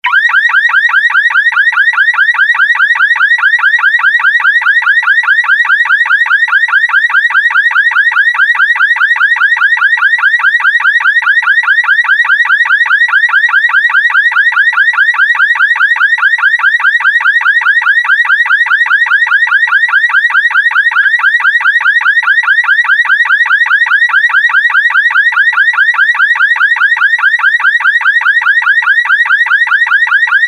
Car alarm sound effect ringtone free download
Sound Effects